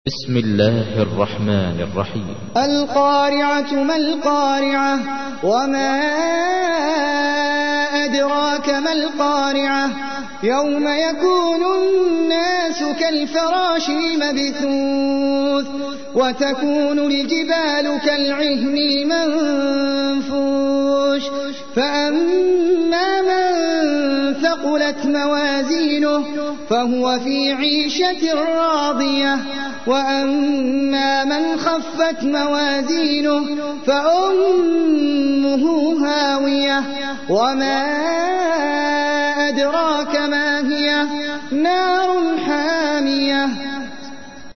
تحميل : 101. سورة القارعة / القارئ احمد العجمي / القرآن الكريم / موقع يا حسين